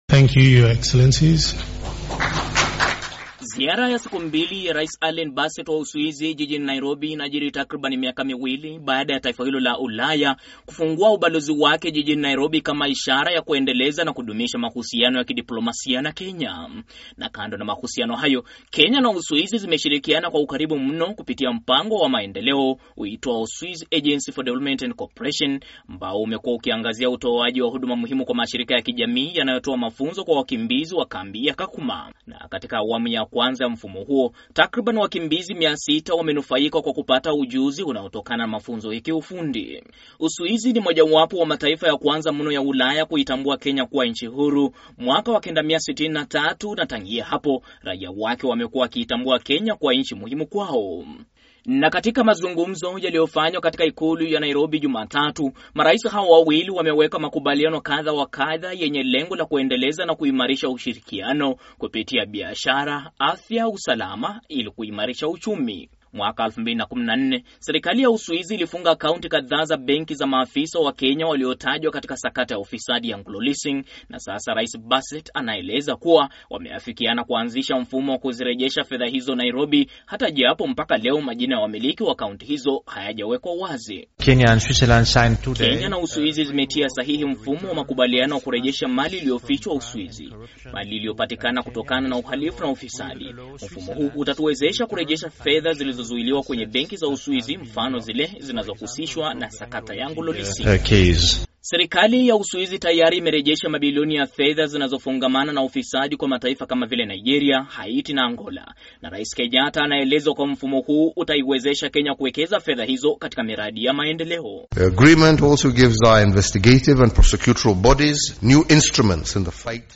Hii hapa ripoti ya mwandishi wa Sauti ya Amerika